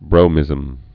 (brōmĭzəm) also bro·min·ism (brōmə-nĭzəm)